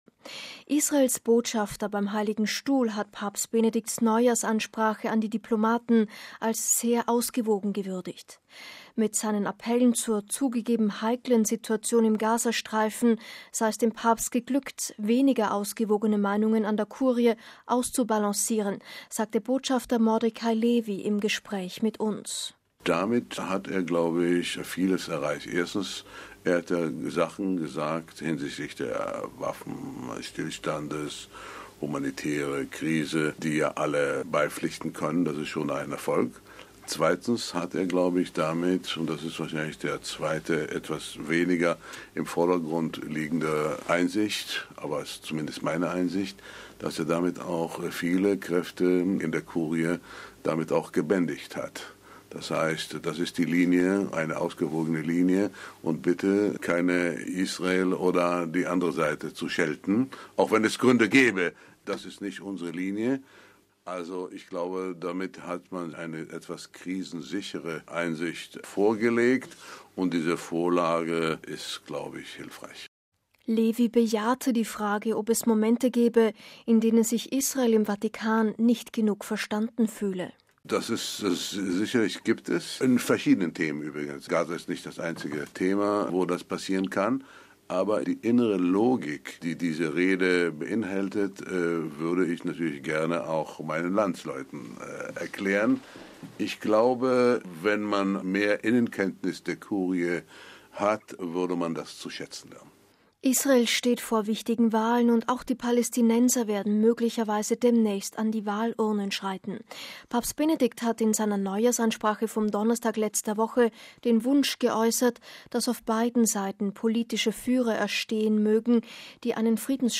MP3 Israels Botschafter beim Heiligen Stuhl hat Papst Benedikts Neujahrsansprache an die Diplomaten als „sehr ausgewogen“ gewürdigt. Mit seinen Appellen zur „zugegeben heiklen Situation“ im Gazastreifen sei es dem Papst geglückt, weniger ausgewogene Meinungen an der Kurie auszubalancieren, sagte Botschafter Mordechai Lewy im Gespräch mit uns.